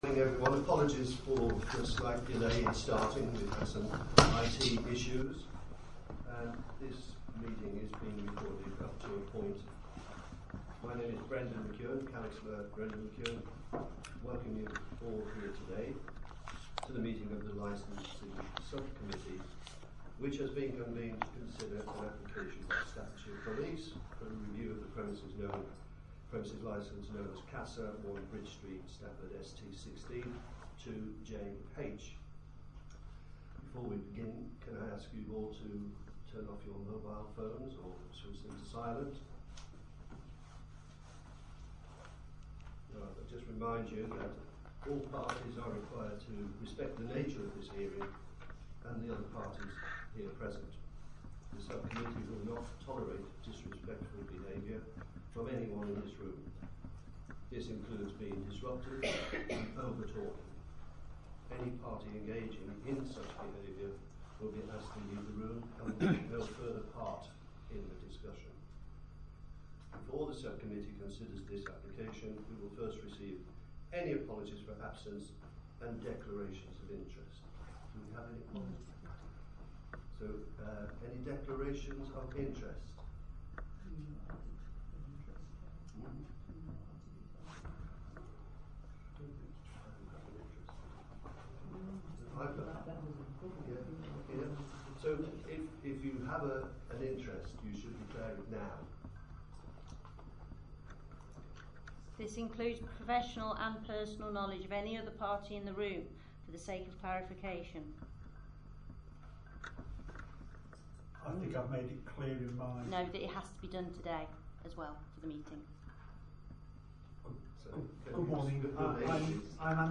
Location: Craddock Room, Civic Centre, Riverside, Stafford